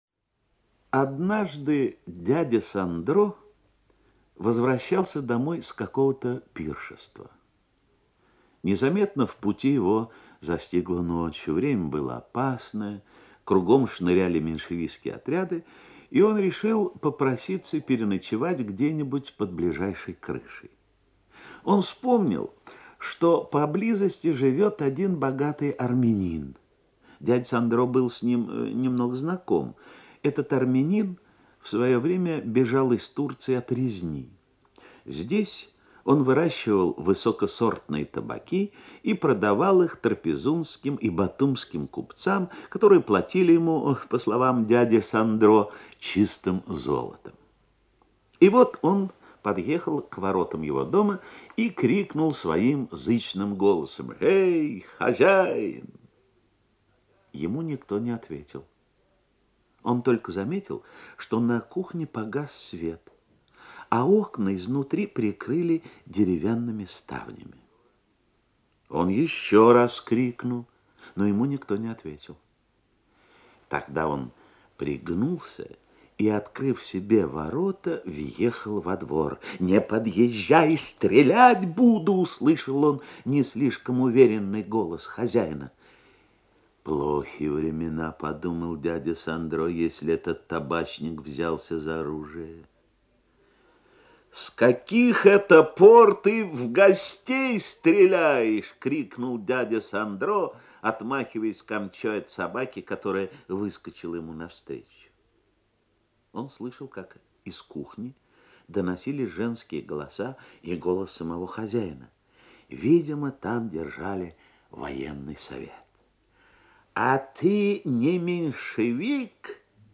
аудиокнига
читает Г.Менглет